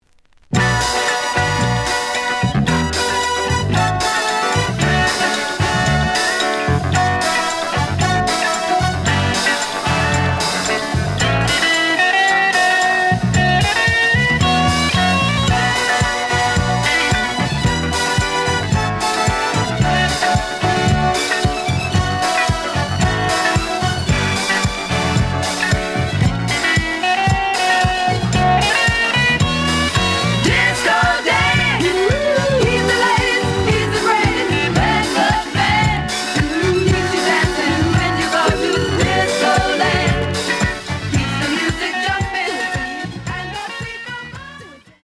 試聴は実際のレコードから録音しています。
●Genre: Disco